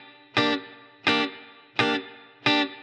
DD_TeleChop_85-Gmin.wav